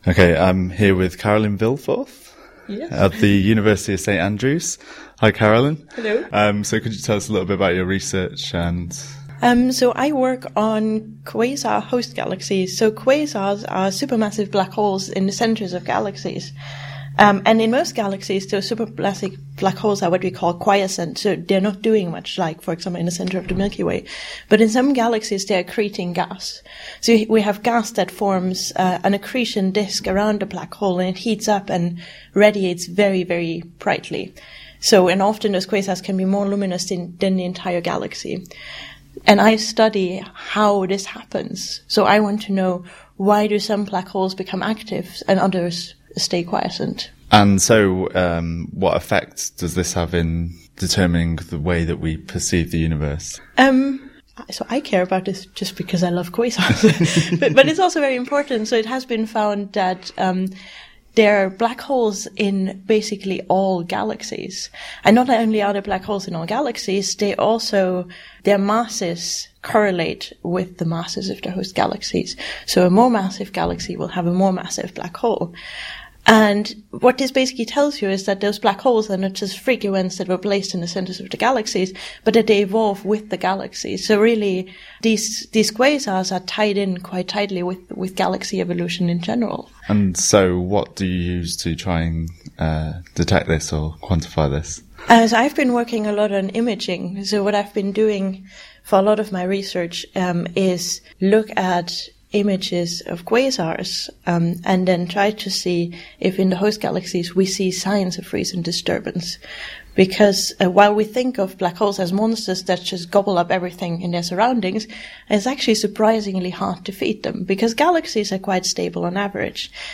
2015nam-jodcast-interview2.mp3